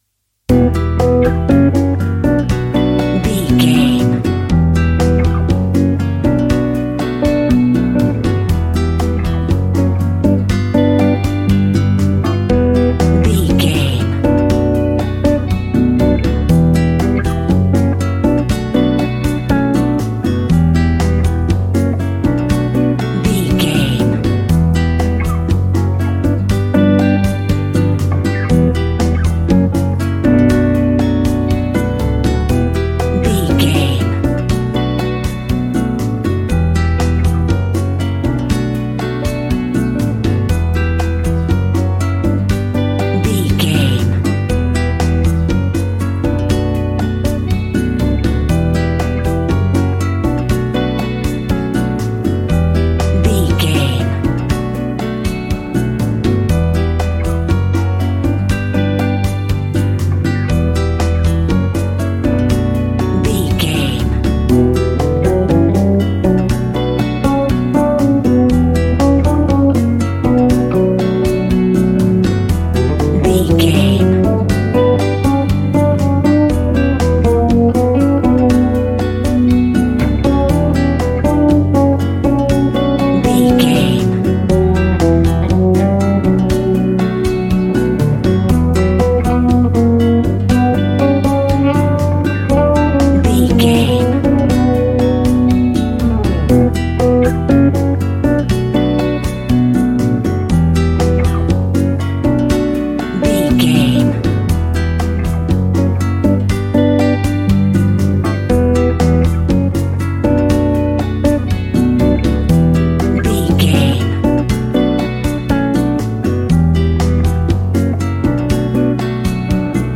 An exotic and colorful piece of Espanic and Latin music.
Aeolian/Minor
funky
energetic
romantic
percussion
electric guitar
acoustic guitar